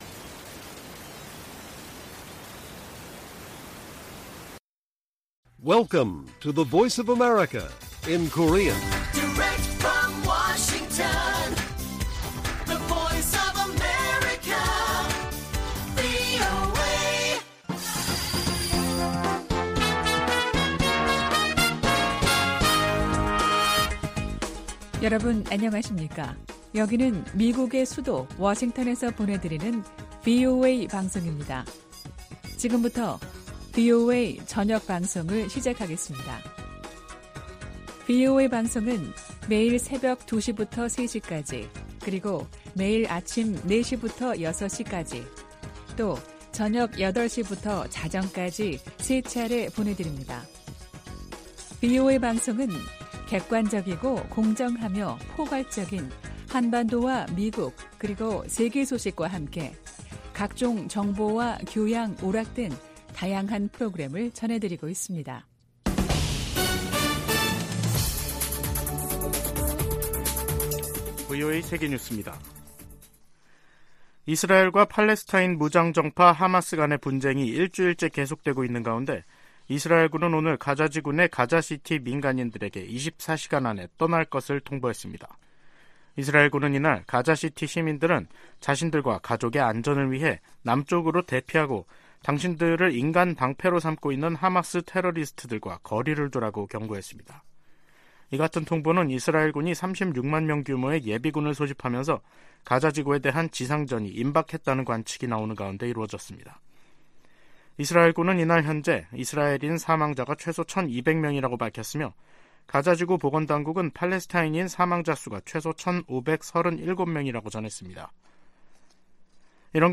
VOA 한국어 간판 뉴스 프로그램 '뉴스 투데이', 2023년 10월 13일 1부 방송입니다. 이스라엘과 하마스의 전쟁 등 중동발 위기가 한반도에 대한 미국의 안보 보장에 영향을 주지 않을 것이라고 백악관이 밝혔습니다. 북한이 하마스처럼 한국을 겨냥해 기습공격을 감행하면 한미연합사령부가 즉각 전면 반격에 나설 것이라고 미국 전문가들이 전망했습니다. 중국 내 많은 북한 주민이 송환된 것으로 보인다고 한국 정부가 밝혔습니다.